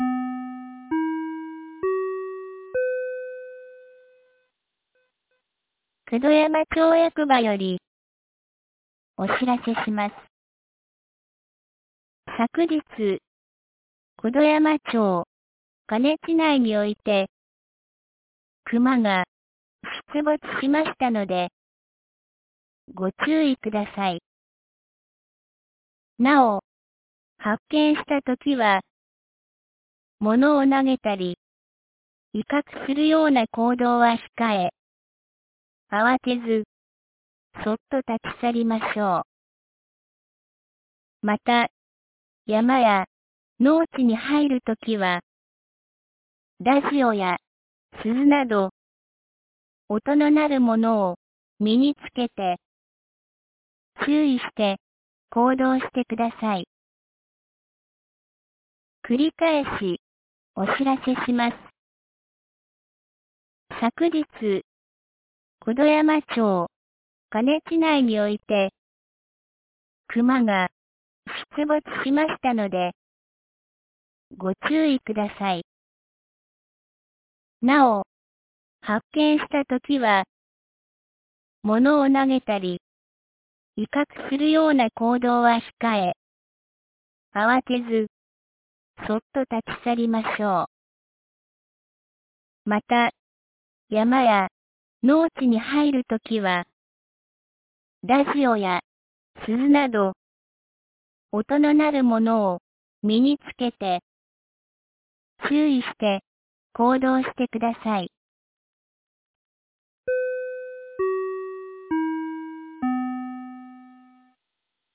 2024年12月02日 11時02分に、九度山町より椎出地区、下古沢地区、中古沢地区、上古沢地区、笠木地区、丹生川地区へ放送がありました。
放送音声